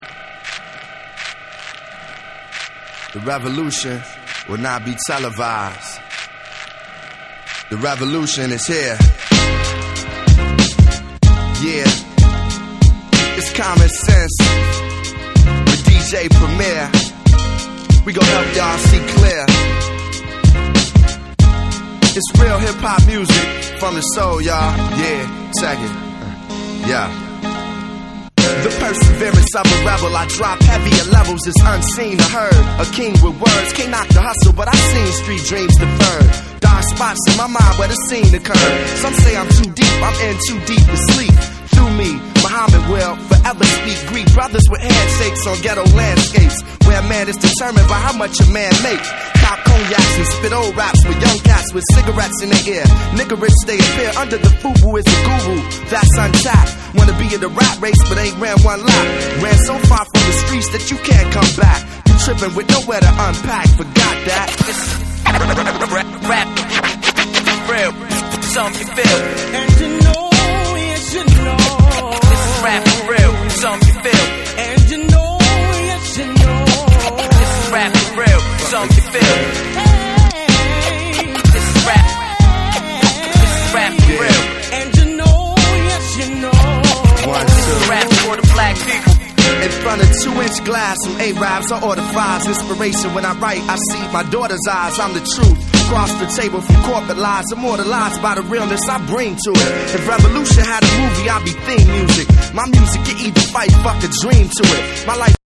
アーバン・メロウなサンプルとファンキーなビート
BREAKBEATS / HIP HOP